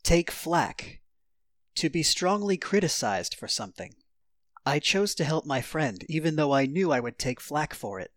ネイティブによる発音は下記のリンクをクリックしてください。